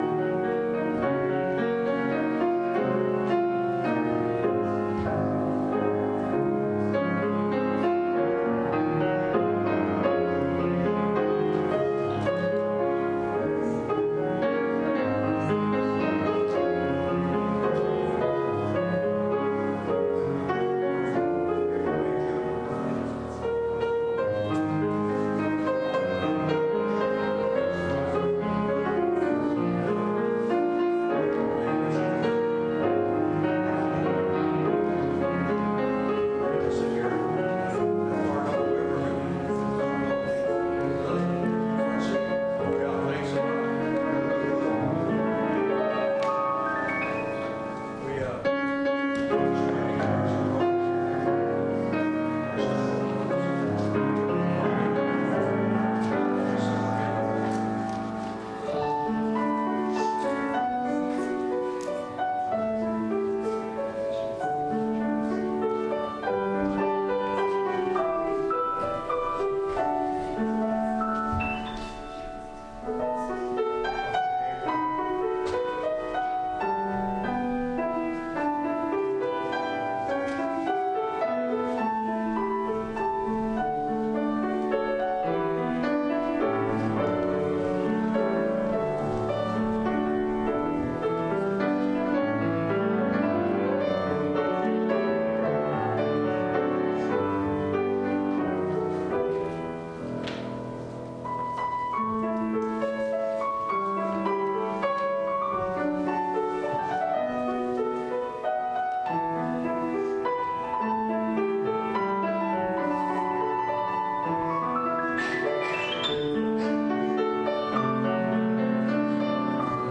Calvin Christian Reformed Church Sermons